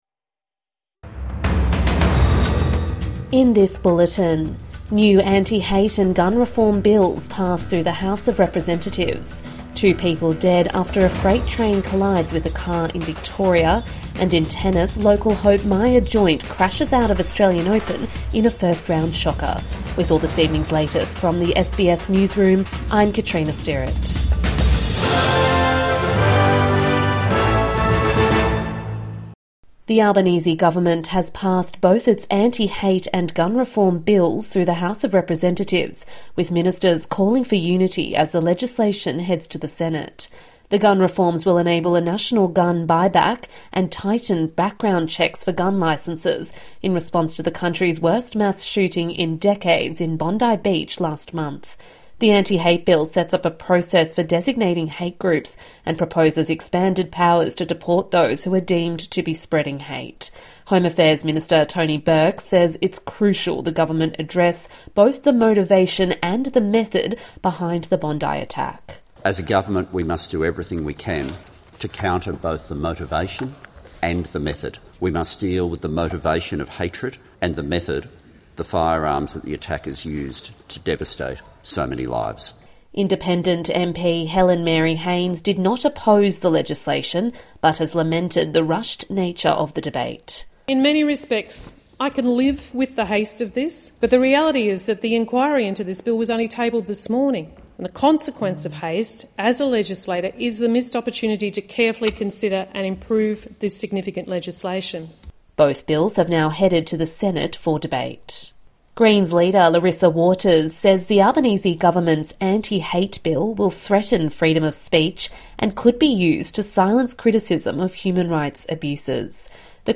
SBS News Updates